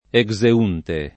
vai all'elenco alfabetico delle voci ingrandisci il carattere 100% rimpicciolisci il carattere stampa invia tramite posta elettronica codividi su Facebook exeunte [ e g@ e 2 nte ] agg. — latinismo per «uscente» in senso cronologico: pergamena del IX secolo exeunte